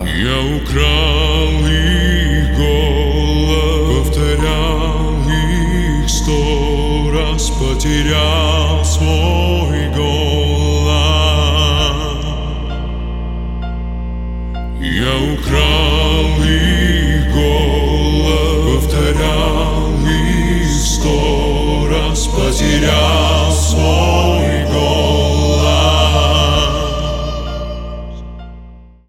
• Качество: 320, Stereo
поп
мужской вокал
рэп
душевные
атмосферные
спокойные
пианино
хор